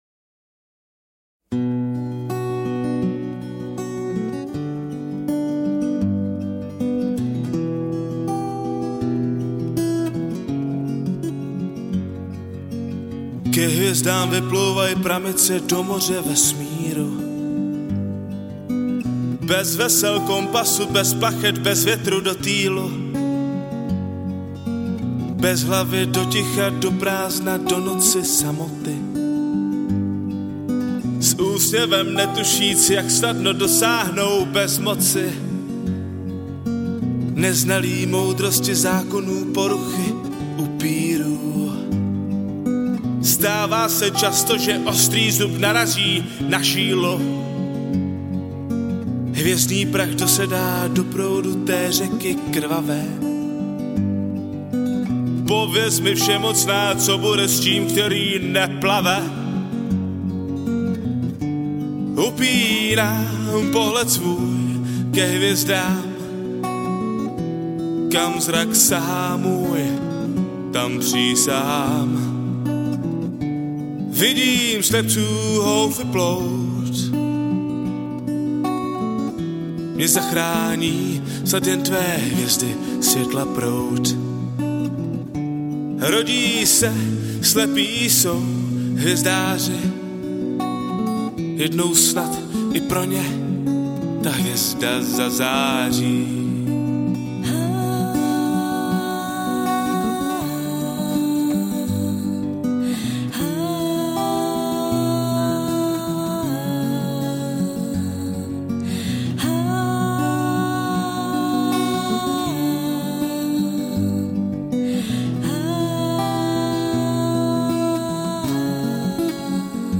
Žánr: Pop